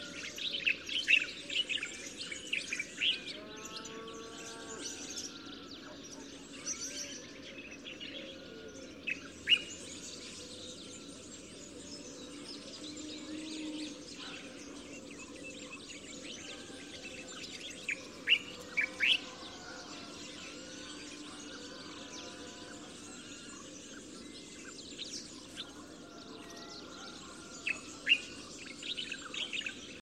Bulbul des jardins - Mes zoazos
bulbul-des-jardins.mp3